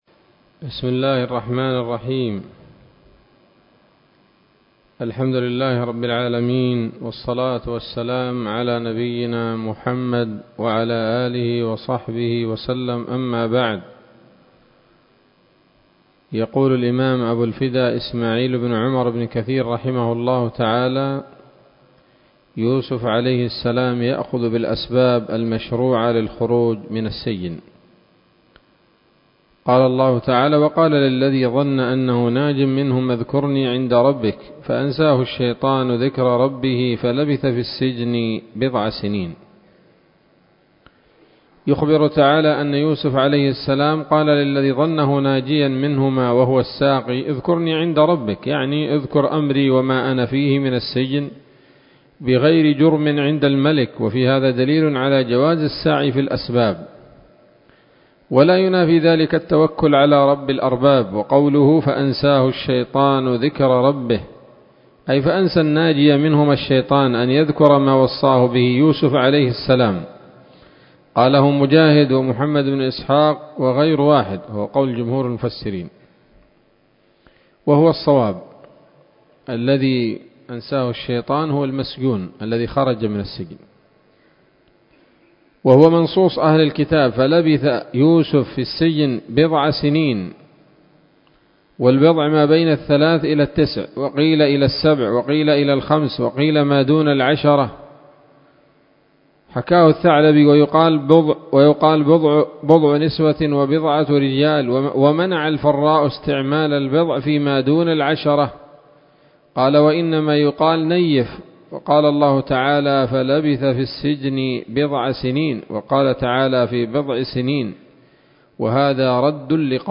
الدرس التاسع والستون من قصص الأنبياء لابن كثير رحمه الله تعالى